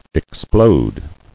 [ex·plode]